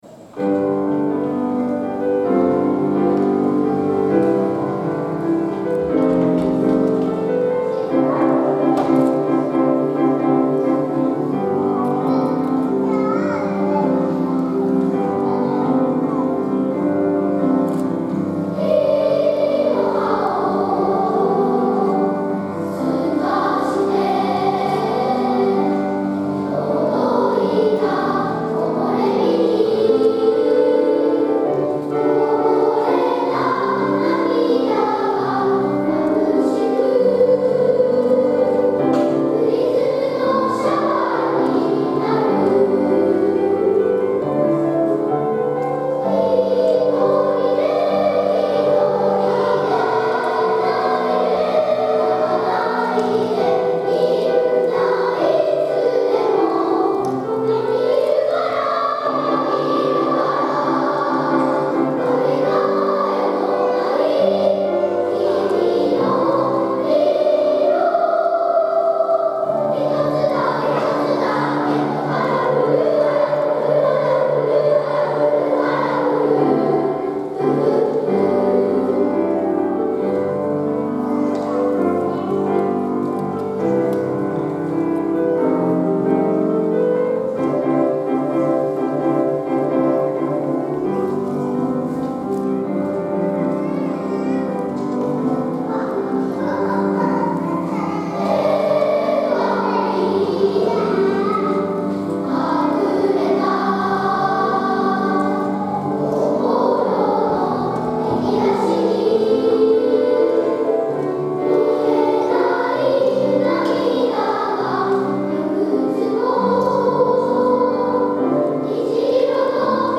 友だちの声をきき合いながら、合唱をつくります。
ソプラノとアルトが追いかけっこするように歌い、ハーモニーをつくります。